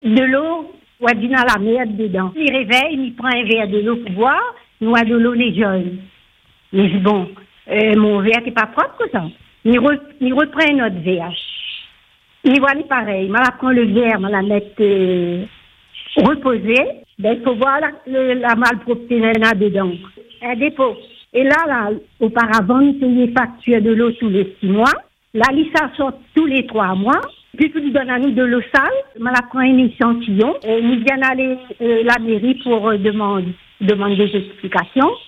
Choquée par la qualité de l’eau, qu’elle juge impropre à la consommation, cette riveraine a décidé de dénoncer la situation sur notre antenne.